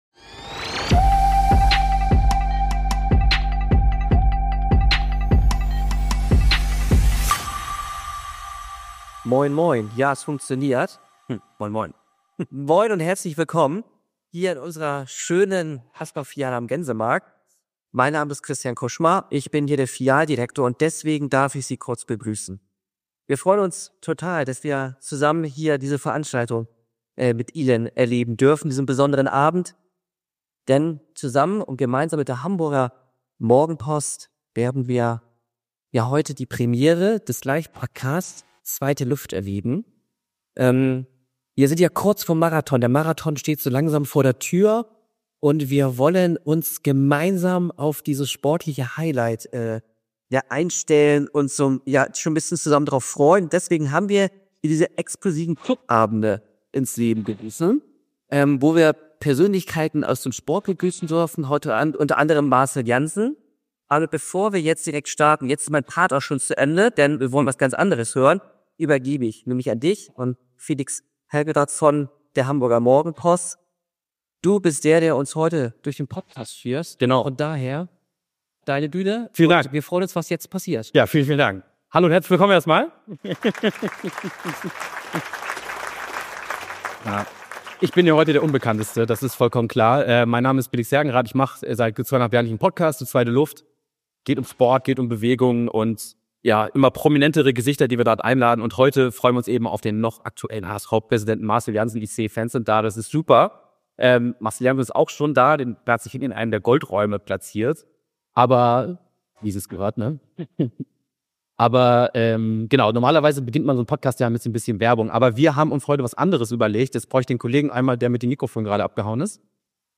Live in Hamburg mit HSV-Präsident und Unternehmer Marcell Jansen (#48) ~ Zweite Luft Podcast